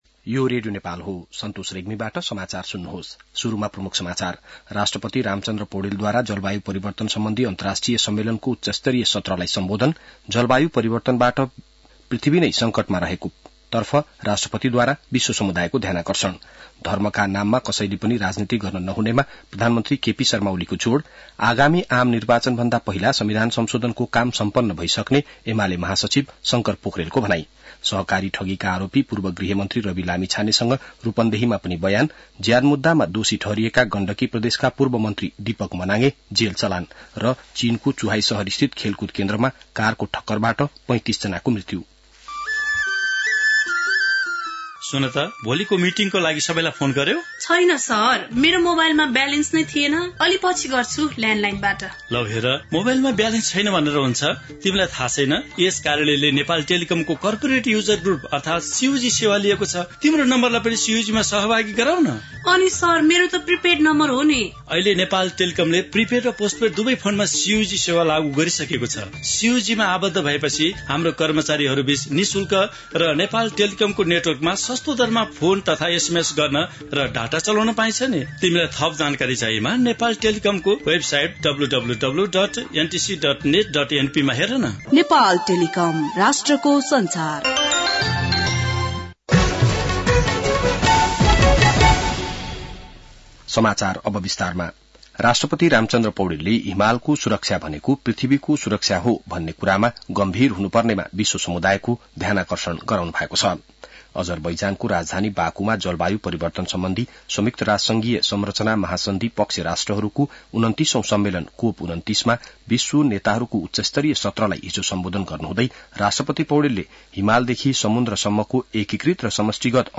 बिहान ७ बजेको नेपाली समाचार : २९ कार्तिक , २०८१